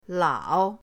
lao3.mp3